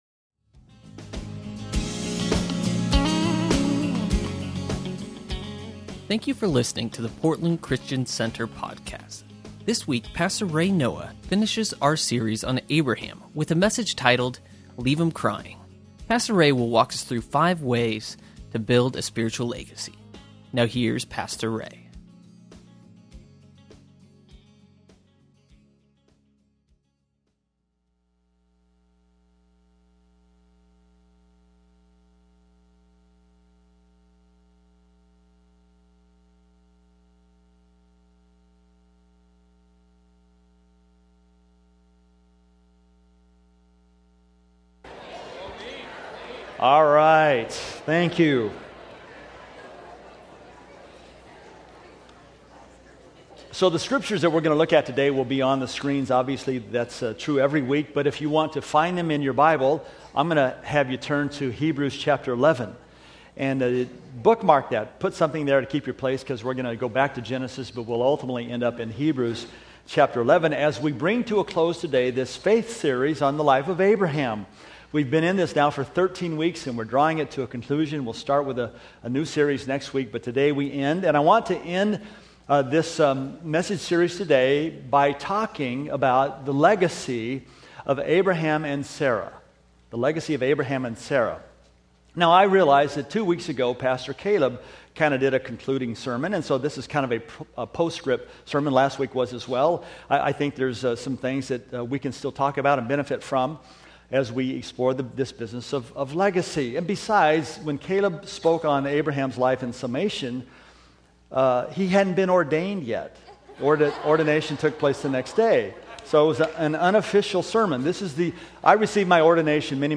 Sunday Messages from Portland Christian Center Leave ‘Em Crying May 11 2014 | 00:30:28 1x 00:00 / 00:30:28 Subscribe Share Spotify RSS Feed Share Link Embed